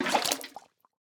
fill_axolotl2.ogg